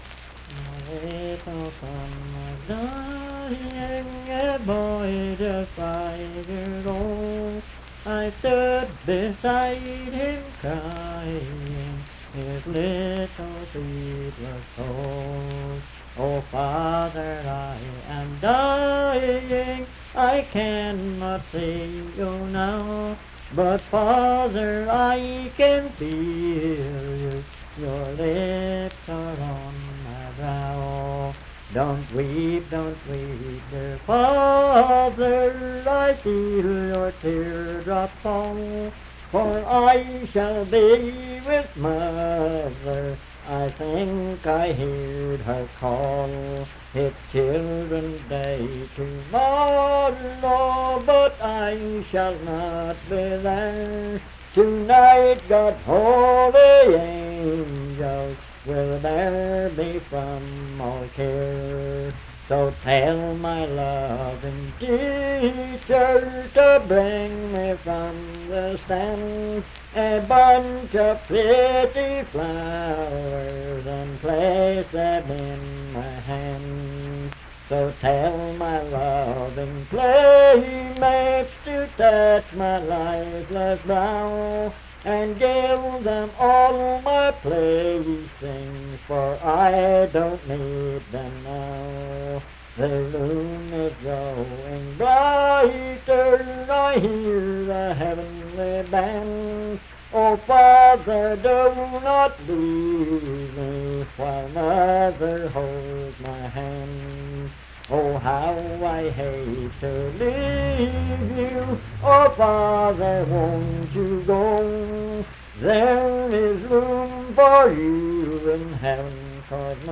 Unaccompanied vocal music
Verse-refrain 8(4). Performed in Dundon, Clay County, WV.
Voice (sung)